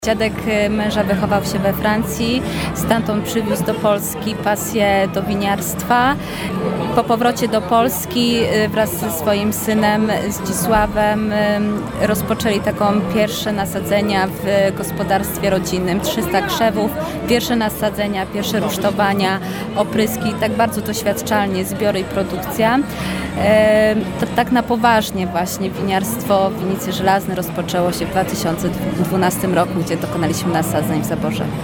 Rozmowy z winiarzami – to jeden ze stałych punktów naszej winobraniowej ramówki. Goście naszego mobilnego studia u stóp ratusza opowiadają jednak nie tylko o historii winiarstwa w naszym regionie, ale również o sobie. I jak zaczęła się ich przygoda z produkcją wina.